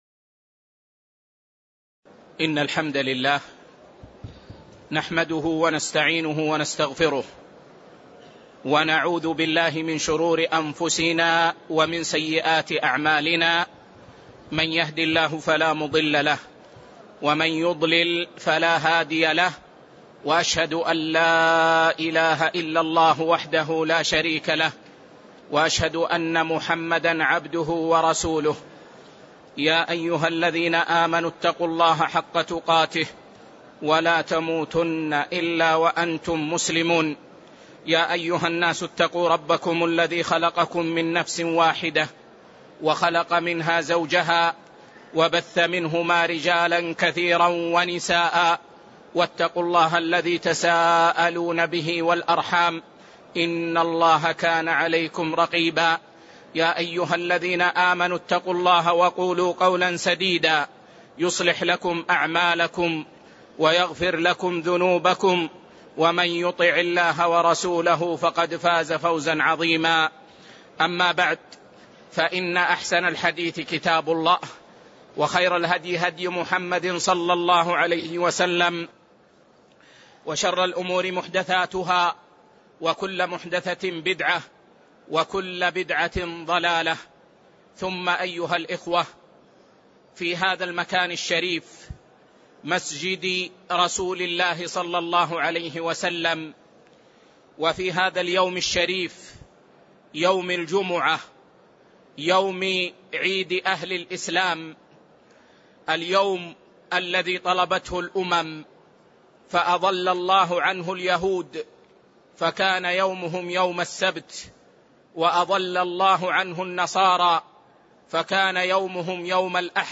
تاريخ النشر ٢٠ ذو الحجة ١٤٣٤ هـ المكان: المسجد النبوي الشيخ